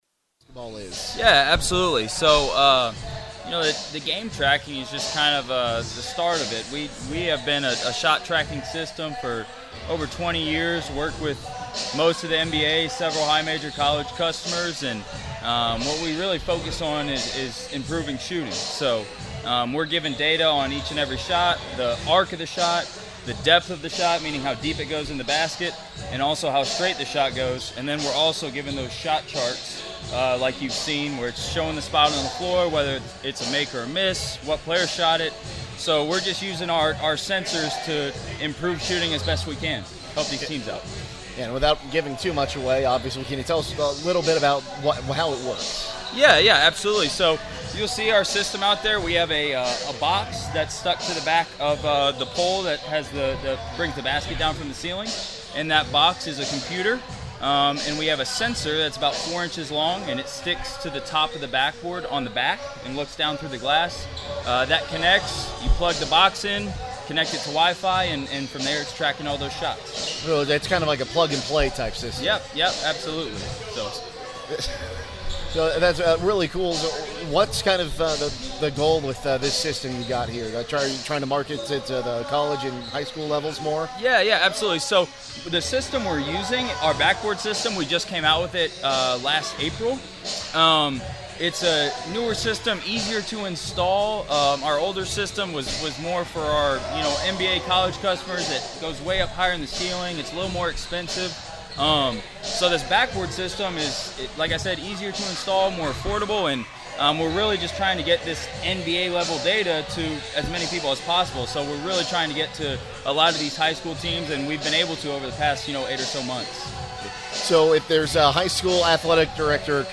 2025 Classic In the Country – Anthony Wayne Player Interviews